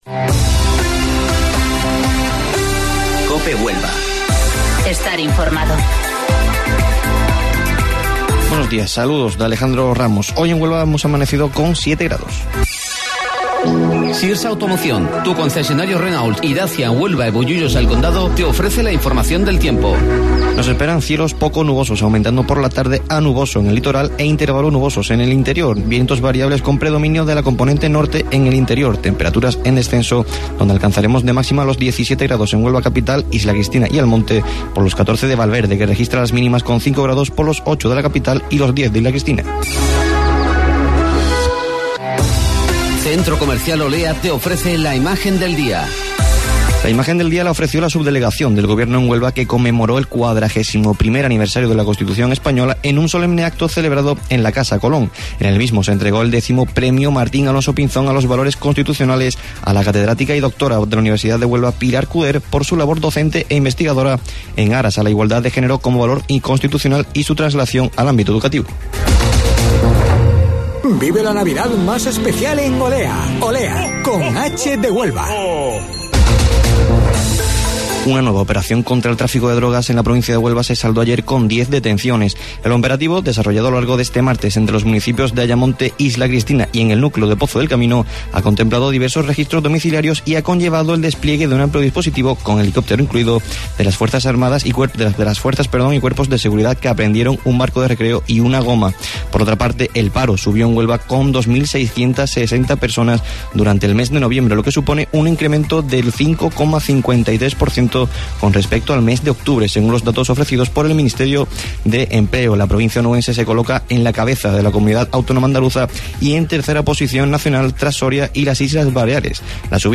AUDIO: Informativo Local 08:25 del 4 de Diciembre